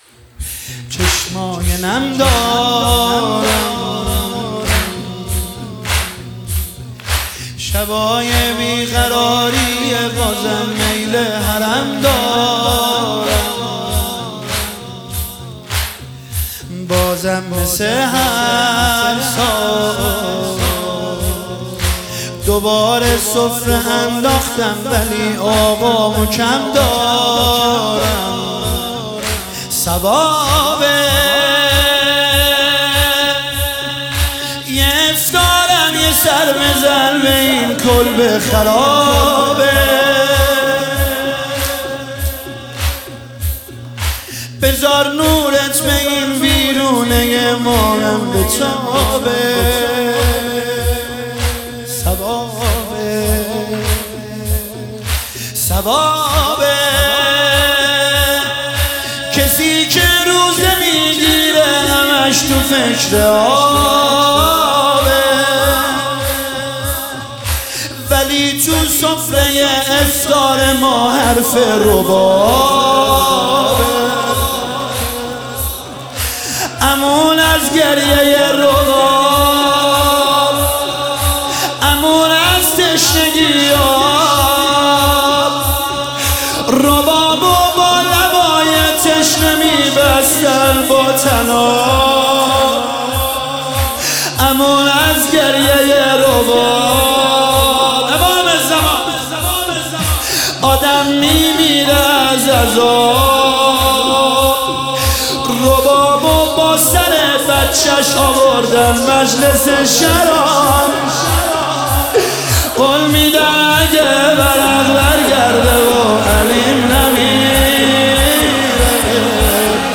مداحی زمینه شب بیست و سوم ماه رمضان شب قدر 1404